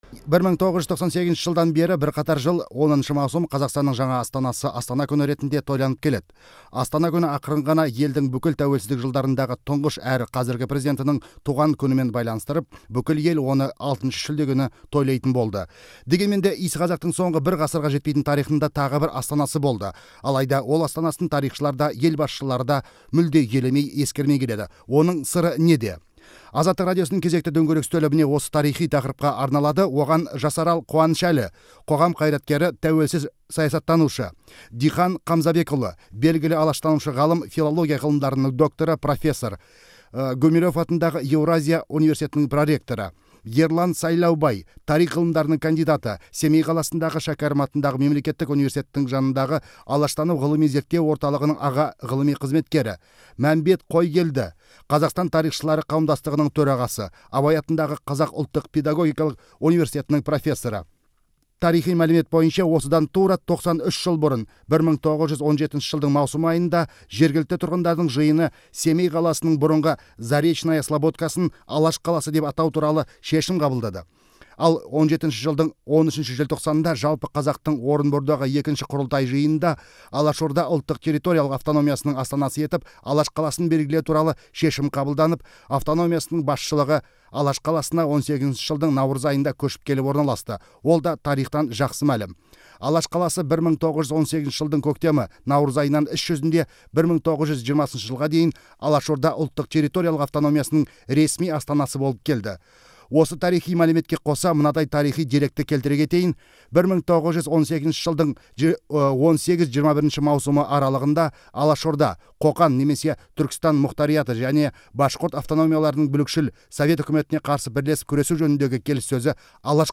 Алаш қаласы тақырыбындағы сұқбатты тыңдаңыз